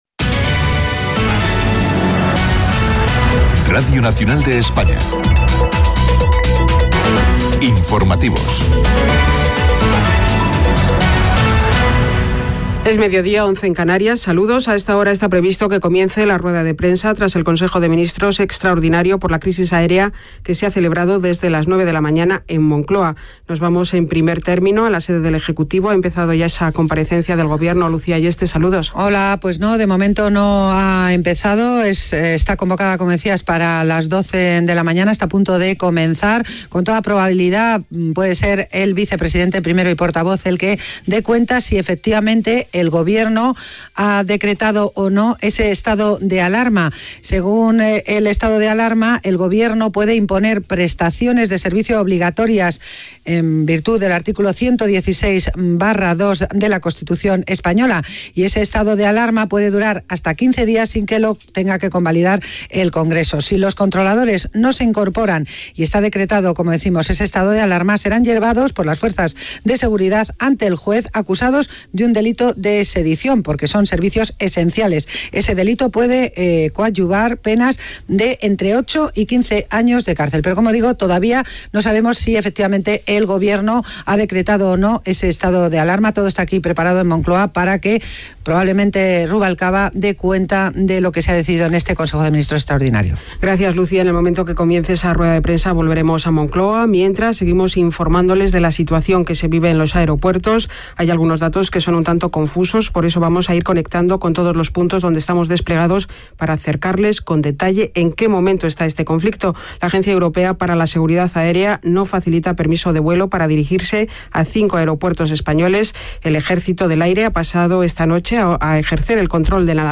Connexió amb els aeroports de Barajas, el Prat i Tenerife. Roda de premsa del Vicepresident del govern espanyol Alfredo Pérez Rubalcaba Gènere radiofònic Informatiu